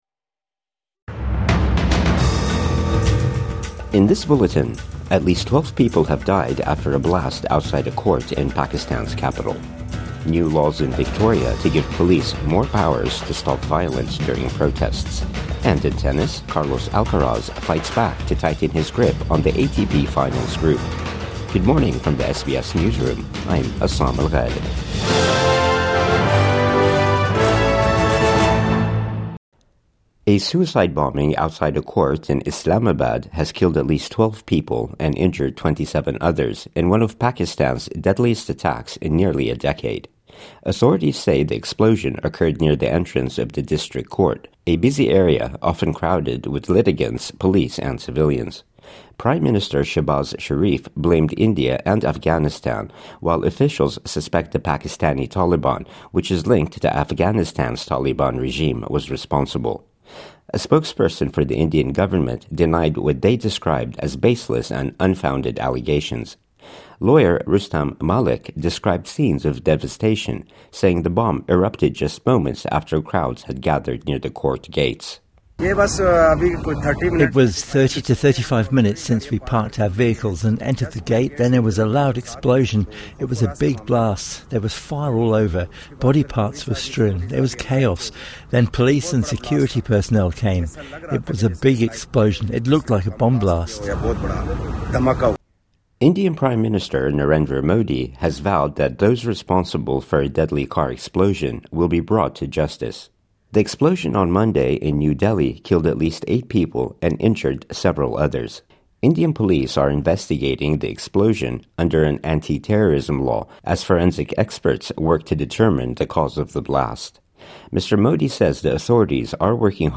Twelve people dead following Islamabad court blast | Morning News Bulletin 12 November 2025